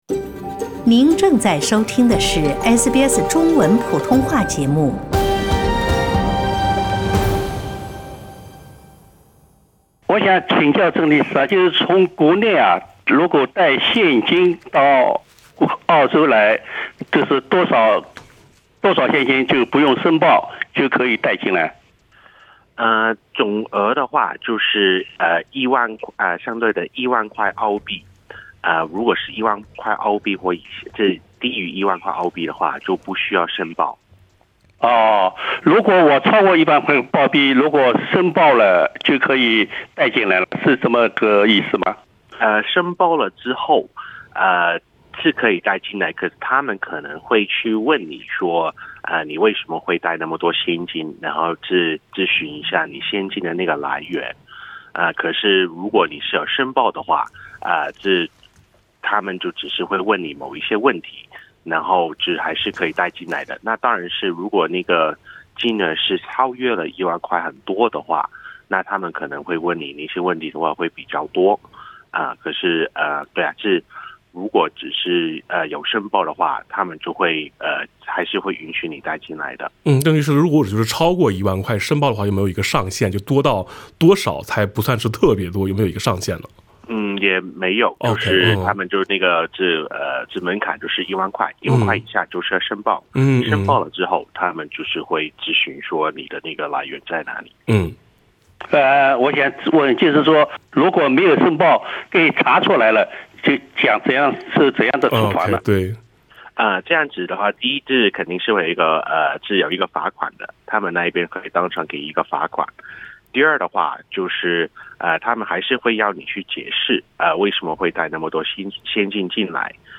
听众热线